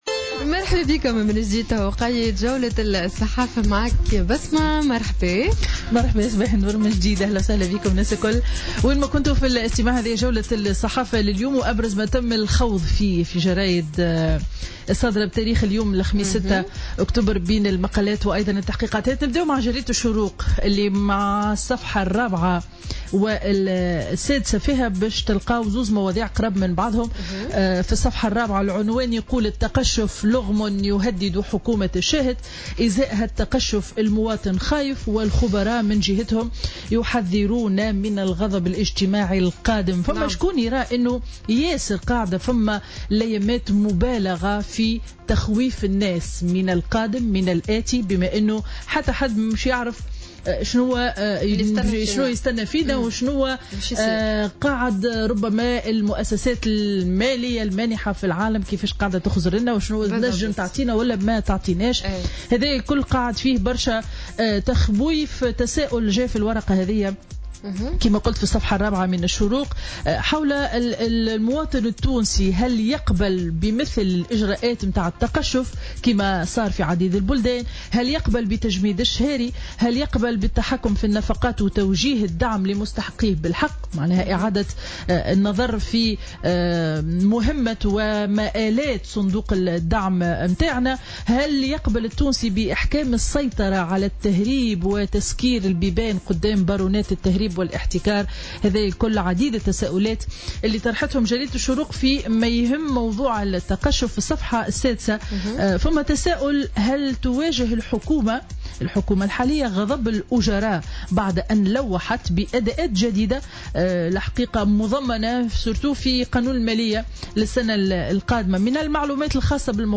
Revue de presse du jeudi 6 octobre 2016